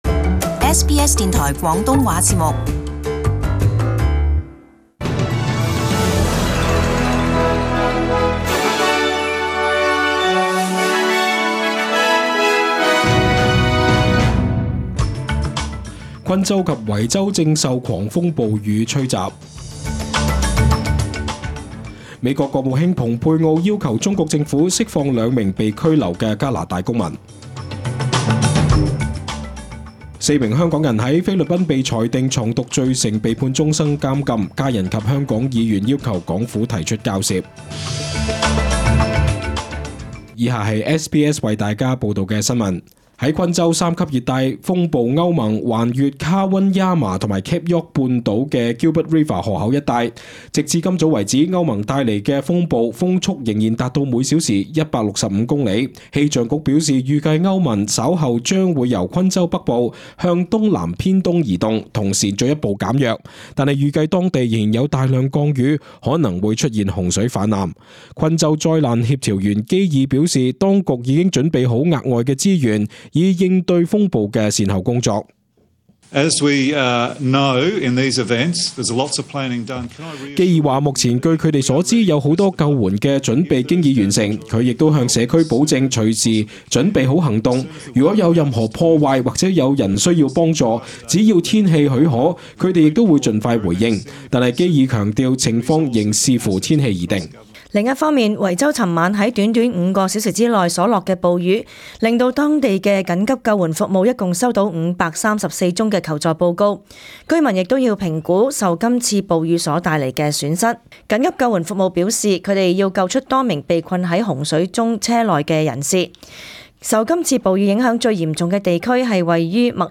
SBS中文新聞 （十二月十五日）